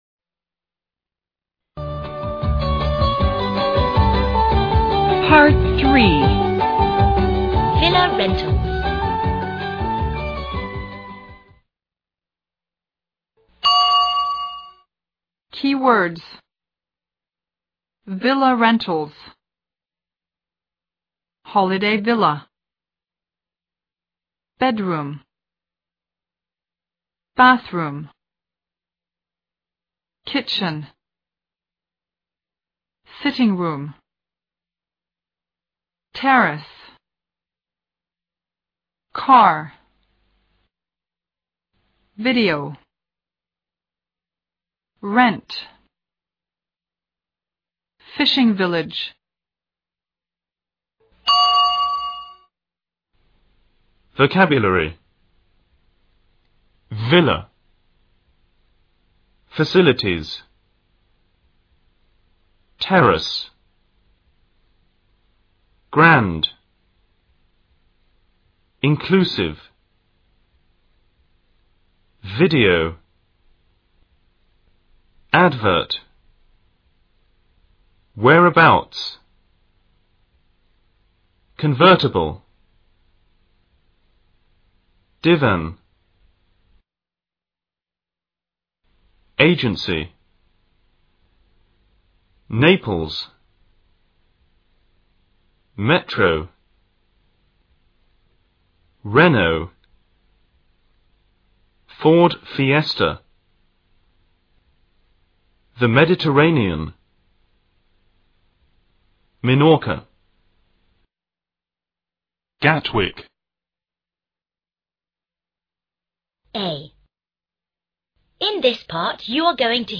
A. In this part, you are going to hear two telephone conversations between a travel agent and two different customers who want to rent holiday homes.